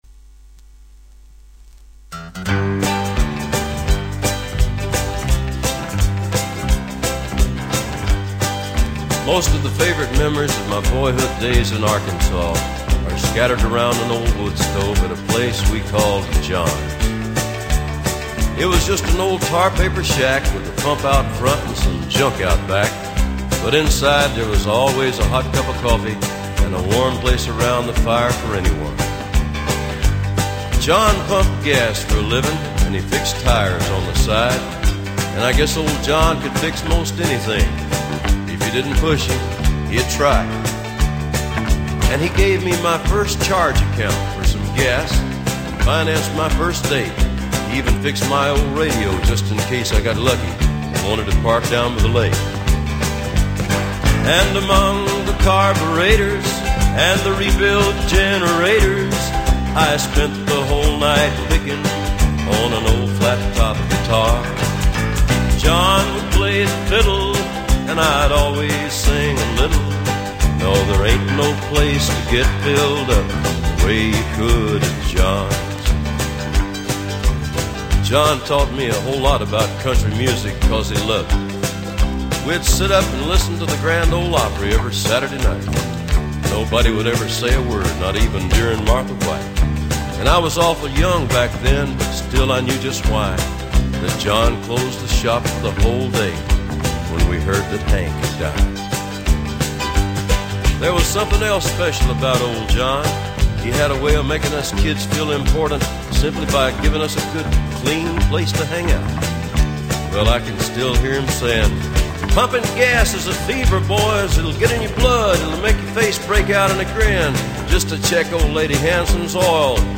Жанр: Blues, Folk, World, & Country